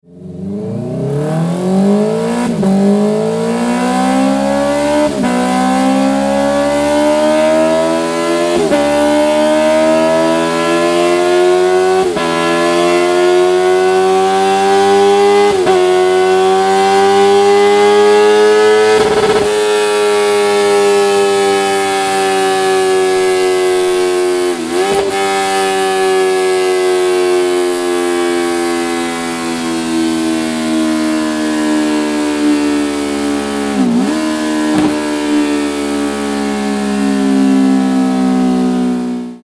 Download 2025-06-12-Honda-CBR-650-R-2024-MK3-Audio.mp3